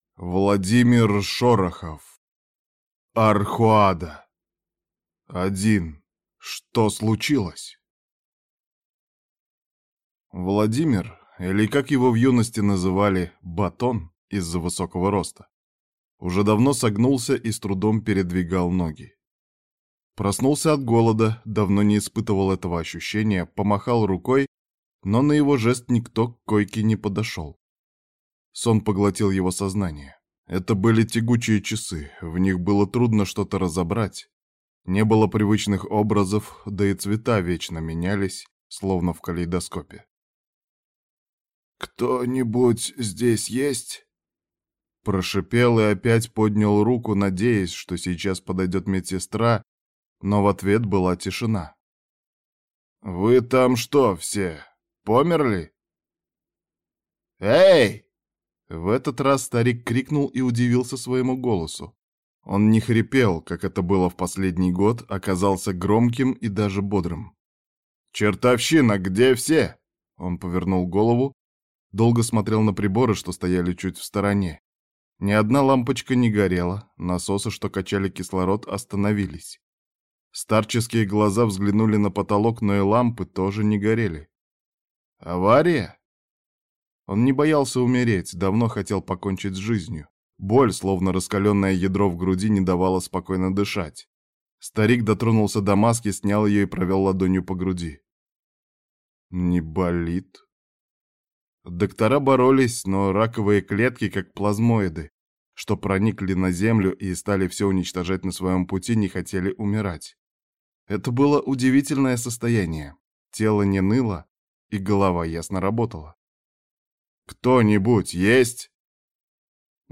Аудиокнига Архоада | Библиотека аудиокниг